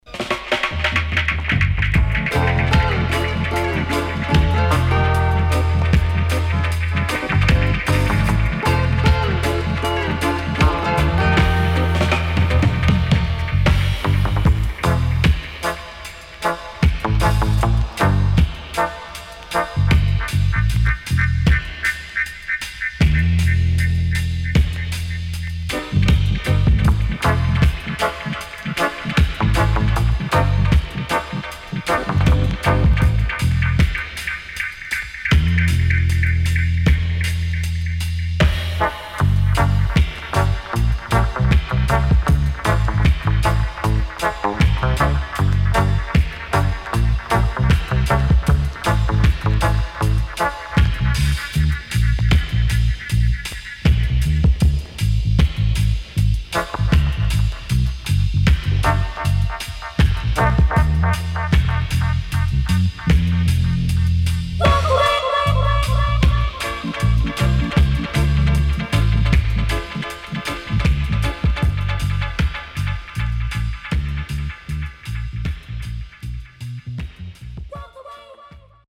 CONDITION SIDE A:VG+〜EX-
SIDE A:少しチリノイズ入りますが良好です。